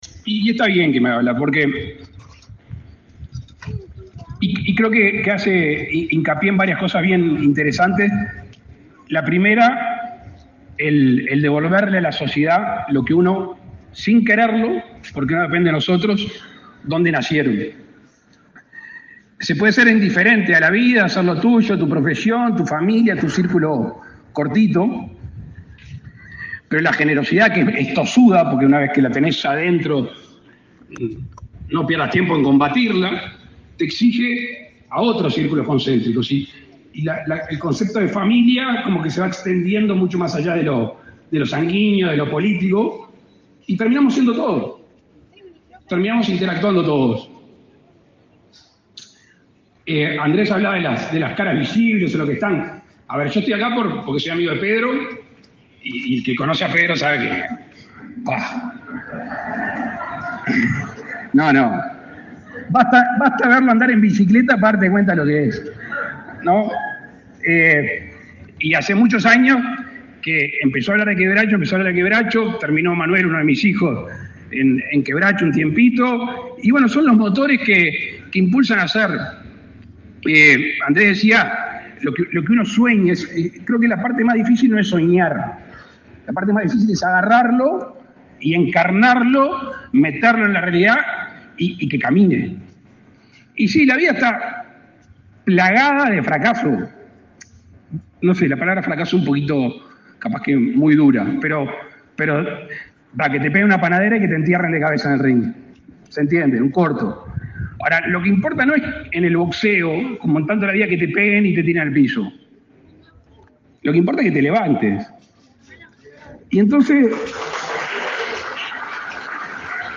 Palabras del presidente de la República, Luis Lacalle Pou
El presidente de la República, Luis Lacalle Pou, participó, este 22 de febrero, en la inauguración del Centro Juvenil Deportivo Quebracho, en el